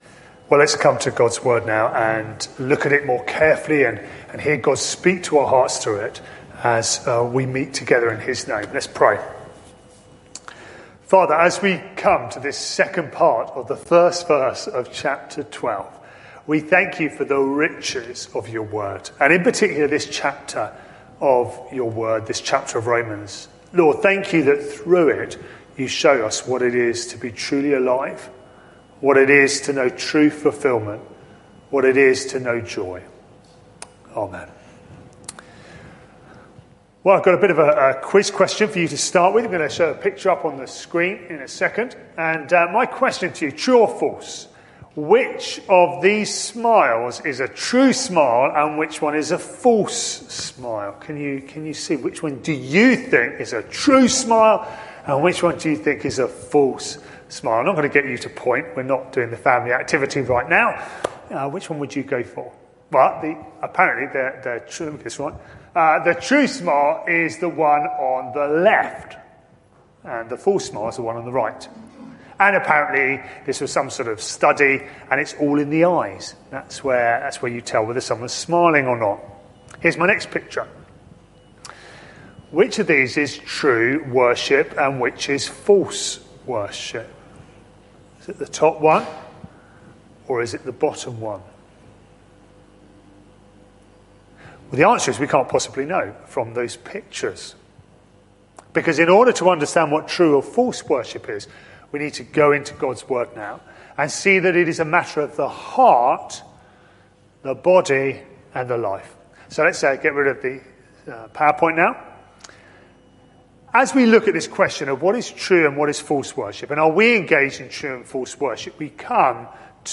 This sermon is part of a series: 5 September 2021